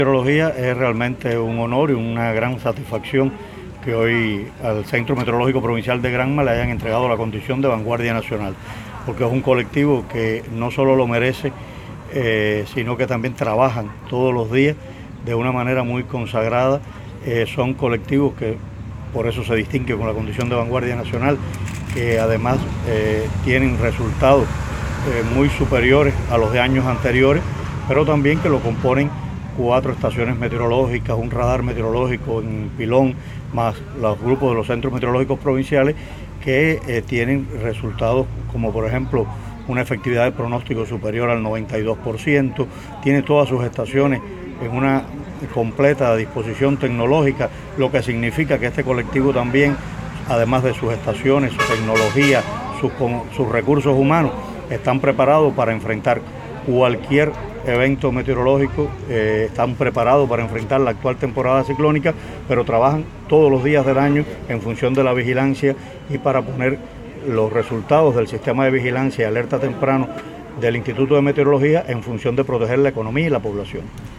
Palabras-de-Celso-Pazos-Alberdi-director-general-del-Instituto-Nacional-de-Meteorología-INSMET.mp3